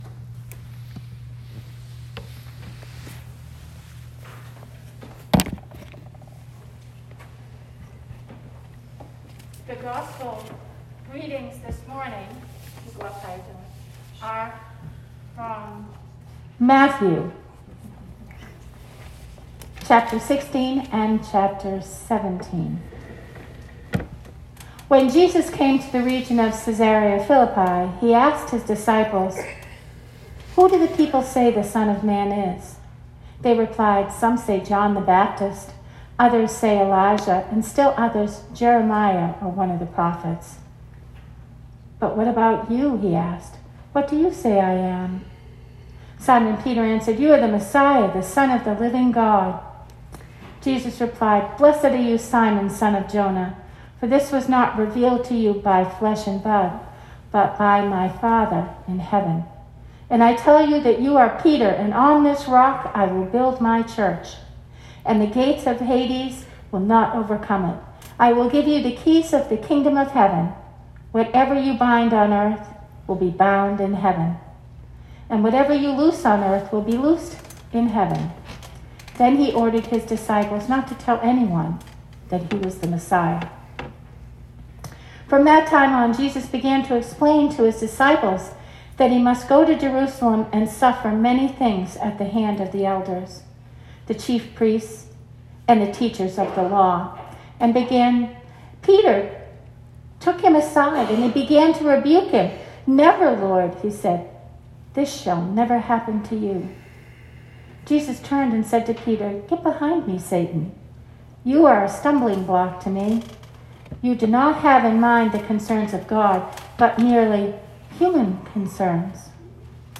Sermon 2020-02-23